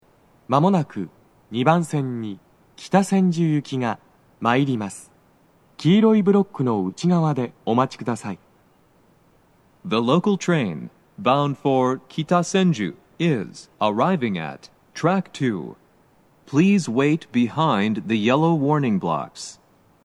スピーカー種類 BOSE天井型
鳴動は、やや遅めです。
接近放送 【男声